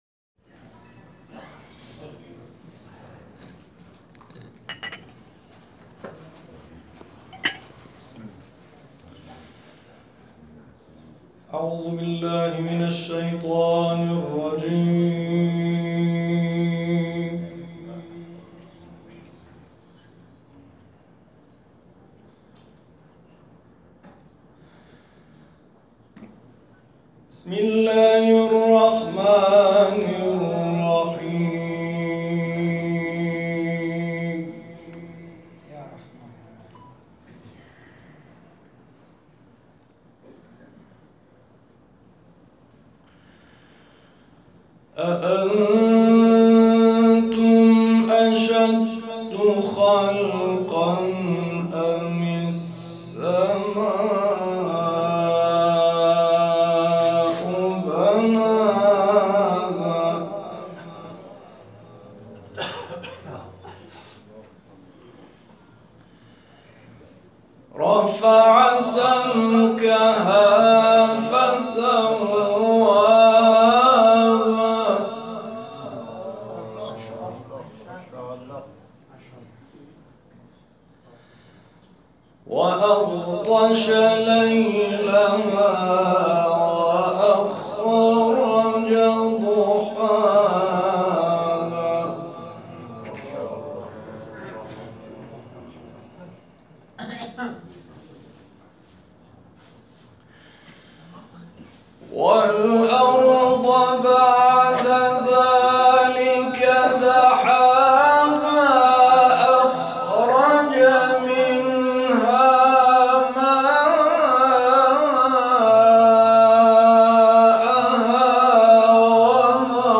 سور رعد، علق  /  هتل هالیدی مکه - حج تمتع 98